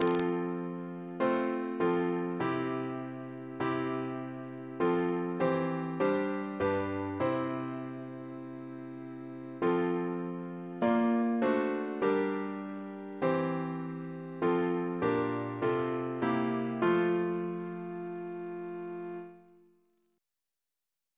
Double chant in F Composer: Henry Aldrich (1647-1710) Reference psalters: ACB: 292; PP/SNCB: 238